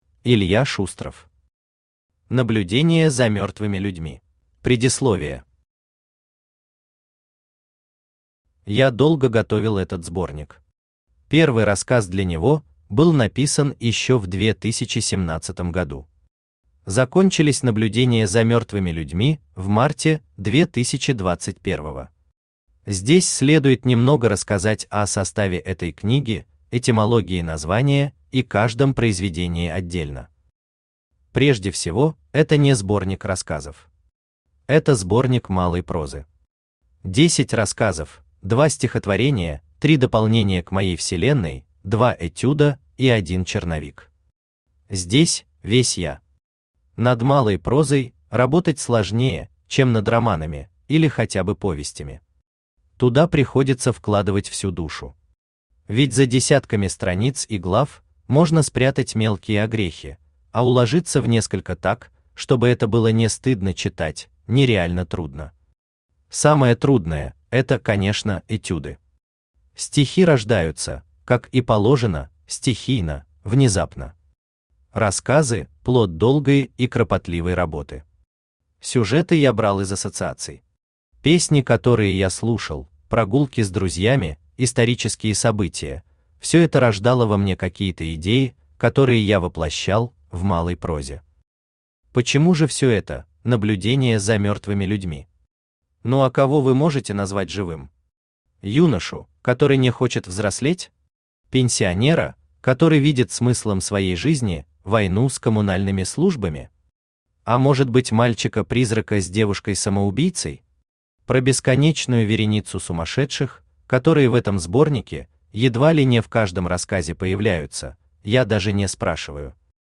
Аудиокнига Наблюдения за мёртвыми людьми | Библиотека аудиокниг
Aудиокнига Наблюдения за мёртвыми людьми Автор Илья Шустров Читает аудиокнигу Авточтец ЛитРес.